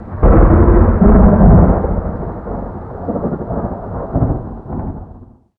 thunder17.ogg